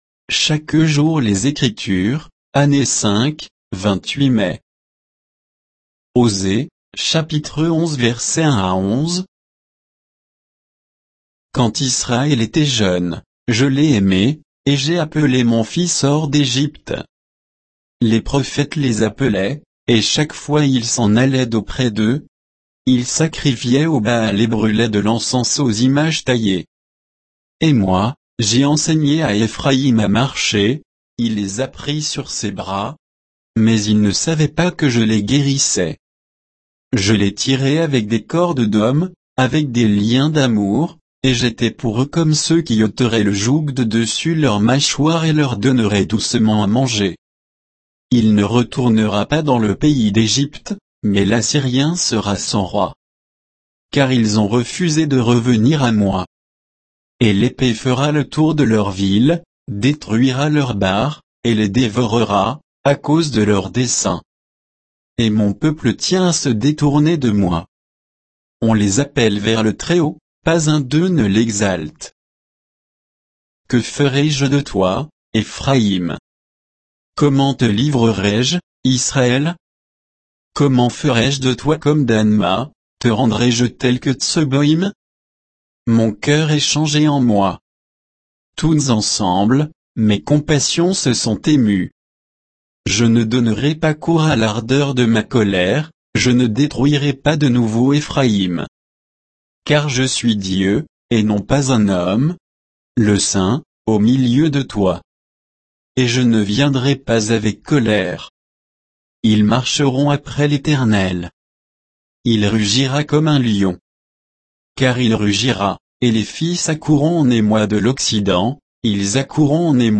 Méditation quoditienne de Chaque jour les Écritures sur Osée 11, 1 à 11